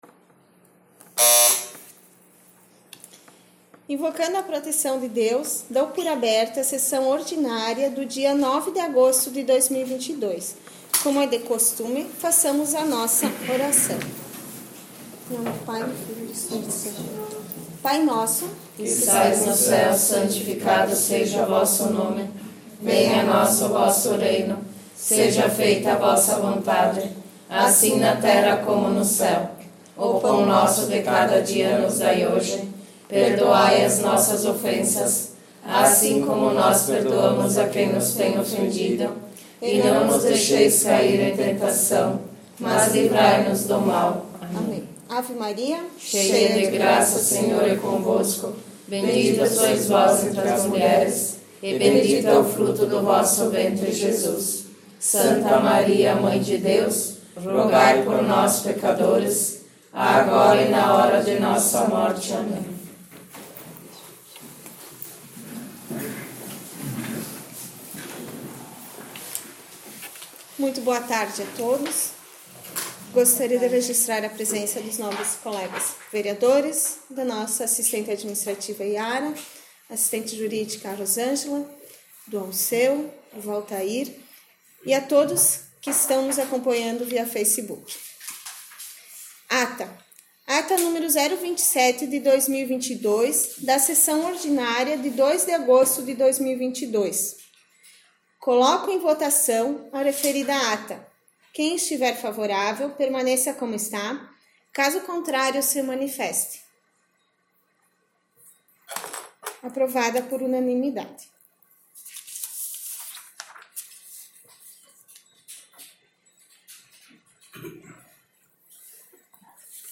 23 - Sessão Ordinária 9 de ago 18.05.mp3 — Câmara Municipal de Boa Vista do Sul
Áudio/Gravação das Sessões da Casa Legislativa Todos os Áudios ÁUDIO SESSÕES 2021 ÁUDIO DAS SESSÕES 2020 ÁUDIO DAS SESSÕES 2019 ÁUDIO DAS SESSÕES 2022 7 - Sessão Ordinária 22 de março 23 - Sessão Ordinária 9 de ago 18.05.mp3